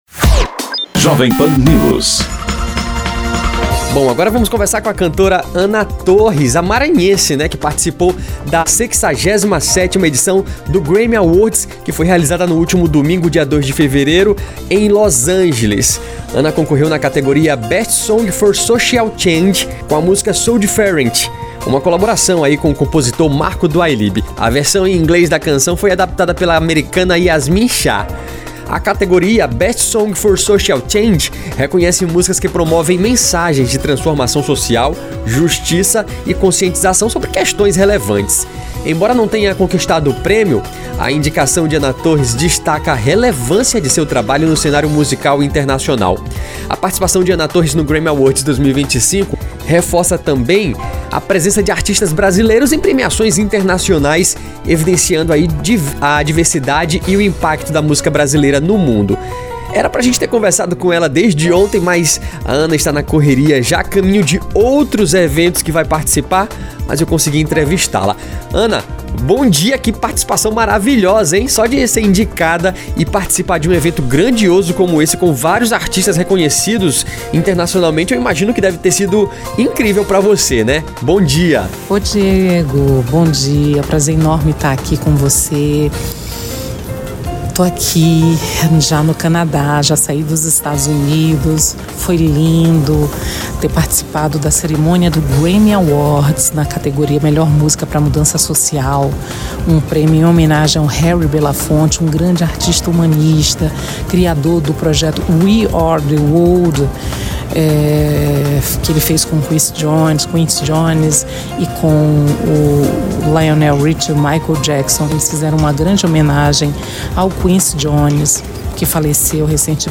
Confira a entrevista com a cantora que foi ao ar na primeira edição do Pan News desta terça-feira (4).